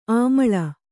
♪ āmaḷa